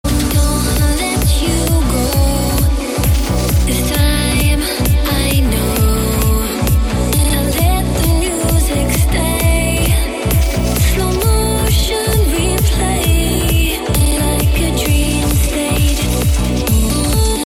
Reggae Ringtones